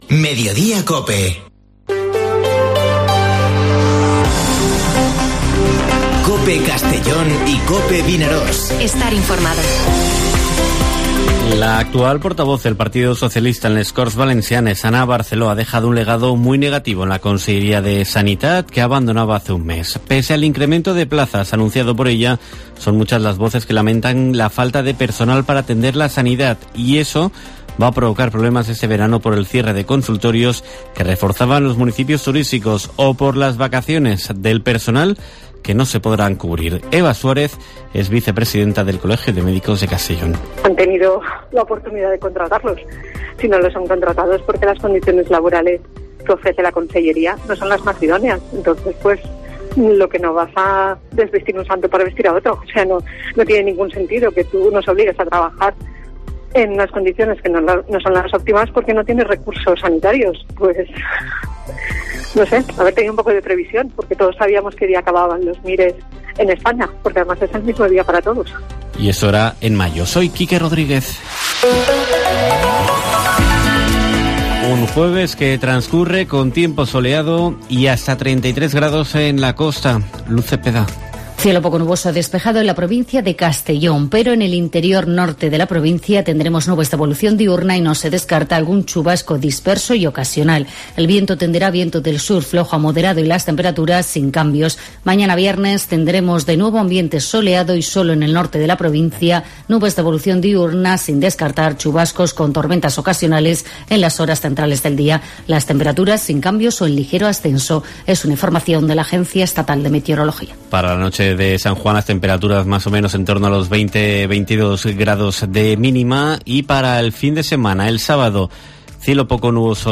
Informativo Mediodía COPE en la provincia de Castellón (23/06/2022)